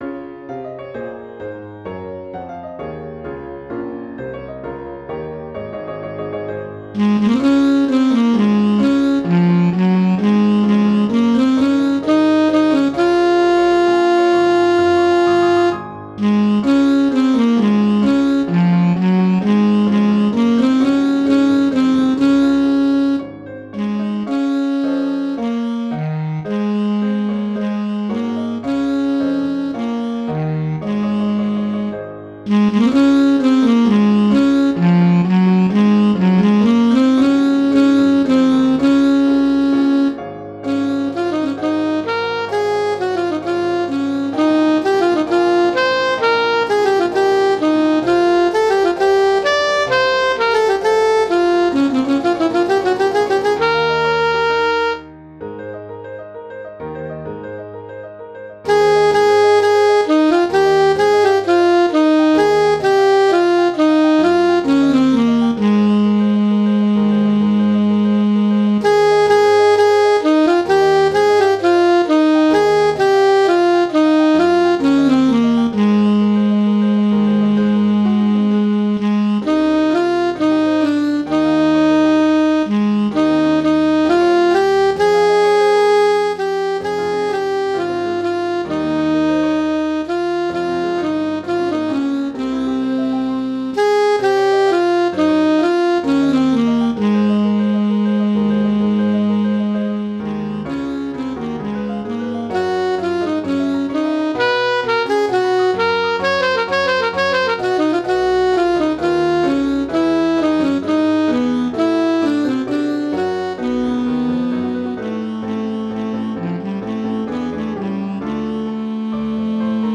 Intermediate Instrumental Solo with Piano Accompaniment.
to the form of a classical sonata